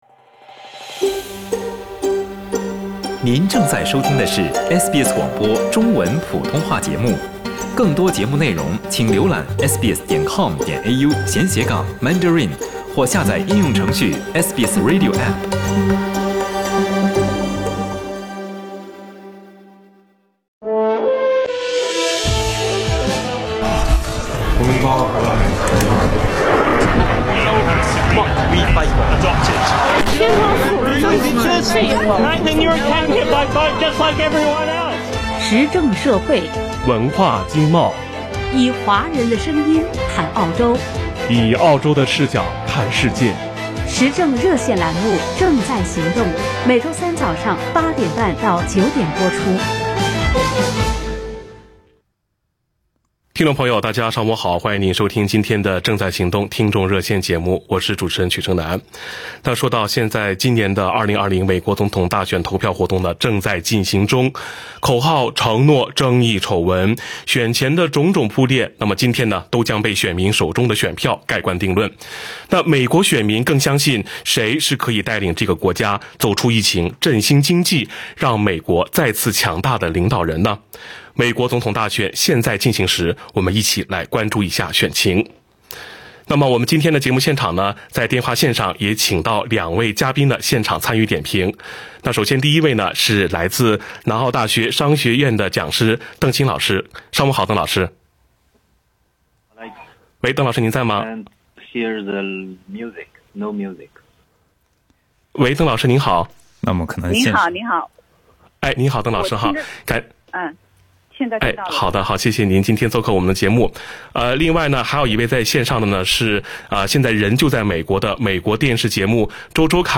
《正在行动》热线2020美国总统大选日特别节目